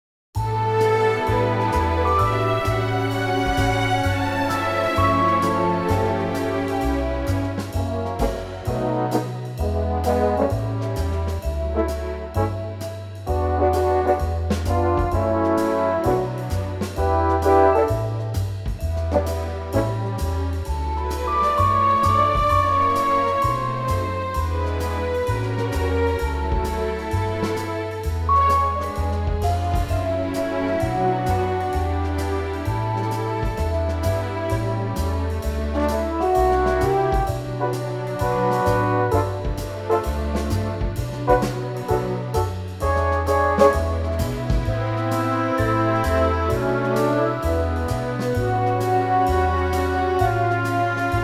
key - D - vocal range - A to B
Lovely and airy orchestral arrangement